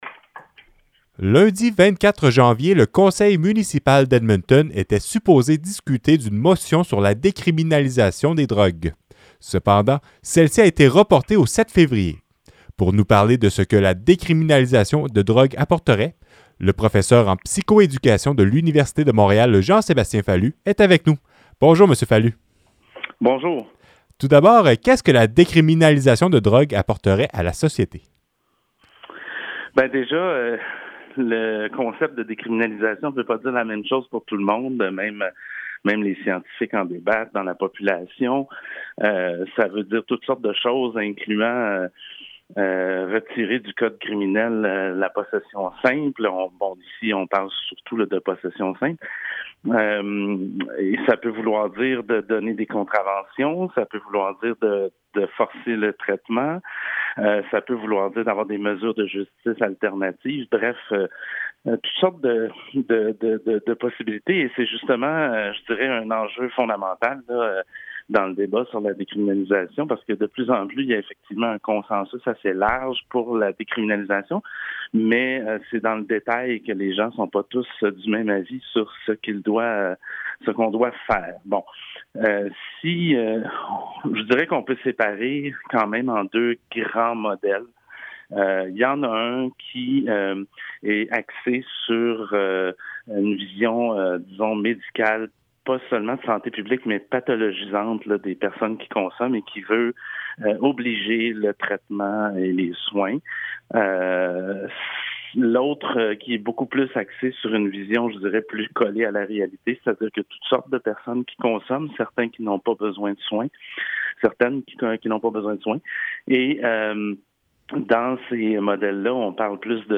Voici l'entrevue intégrale